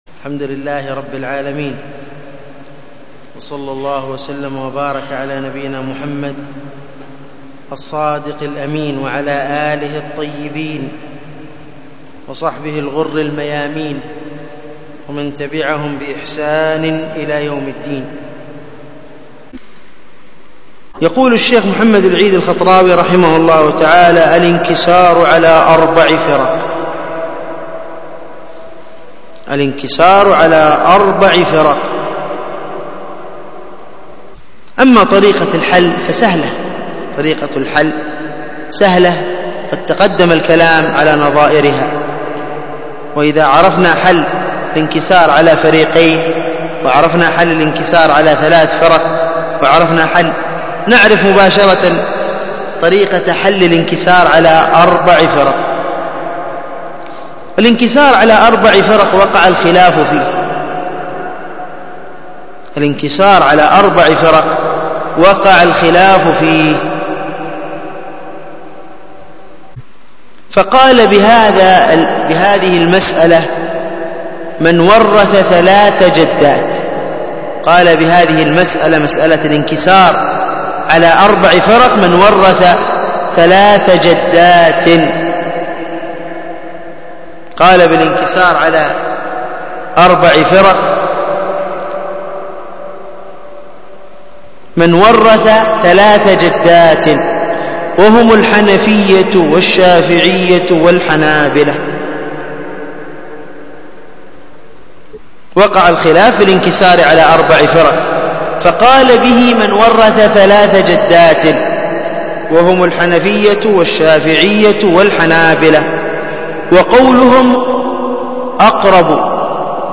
الدرس في شرح لمعة الإعتقاد 29